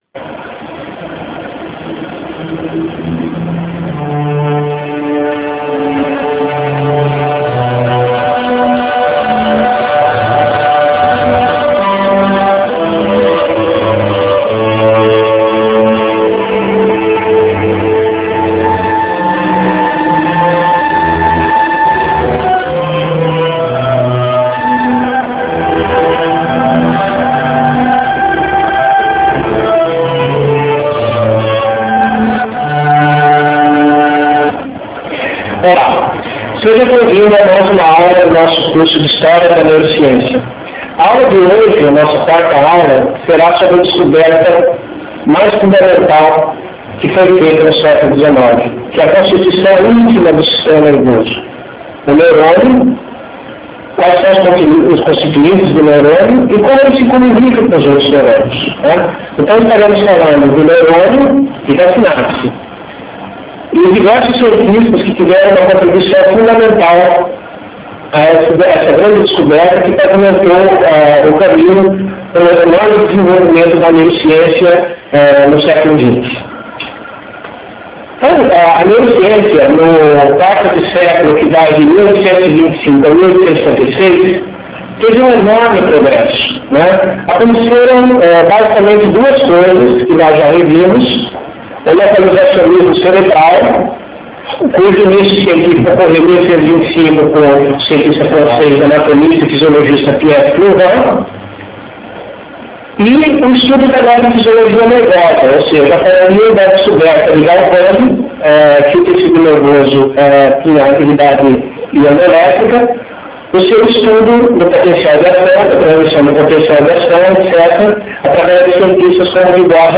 Assista a aula expositiva em rempo real, no formato de vídeo em RealMedia disponível no site do curso.